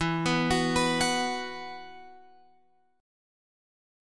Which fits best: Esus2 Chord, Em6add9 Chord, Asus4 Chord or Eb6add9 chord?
Esus2 Chord